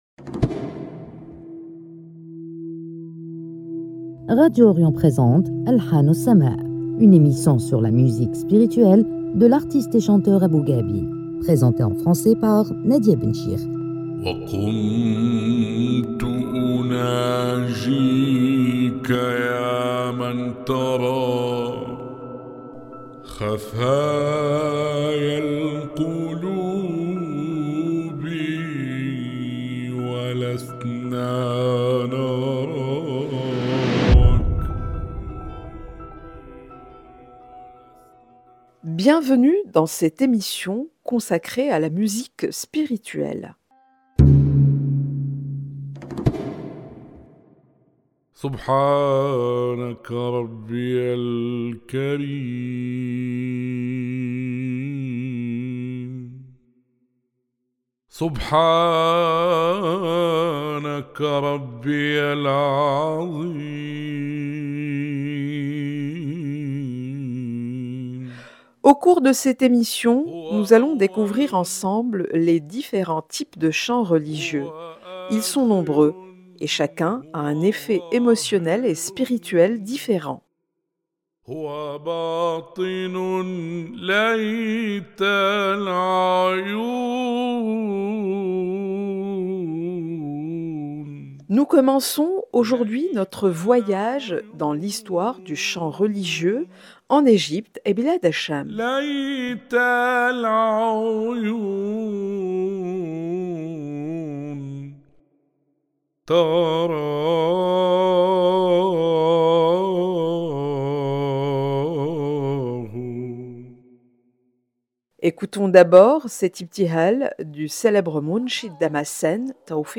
présentée en français